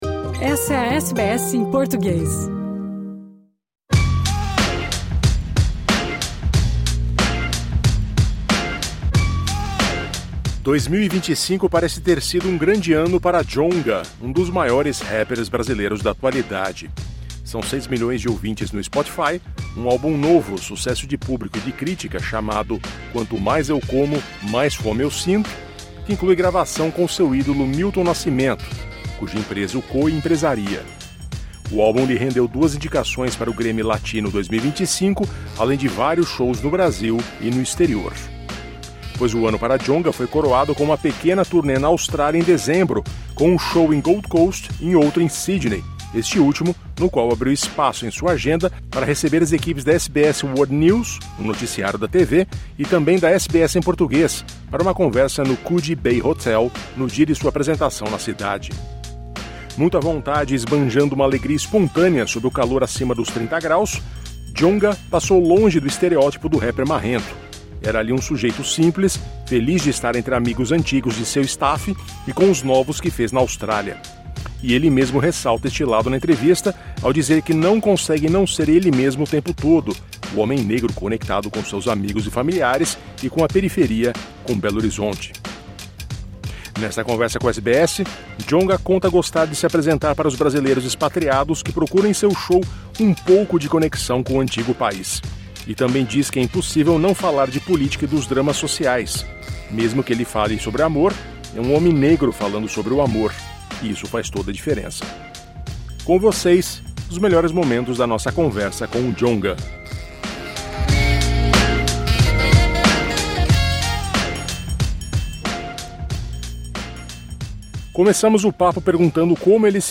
Um dos maiores rappers brasileiros do momento, Djonga conversou com a SBS em meio aos dois shows que fez na Austrália. Ele falou sobre como sua arte se encontra com a questão da negritude, da importância de mostrar aos jovens negros que eles também podem um dia chegar lá, apesar das dificuldades, e de sua parceria com Milton Nascimento, que lhe rendeu indicações ao Grammy Latino.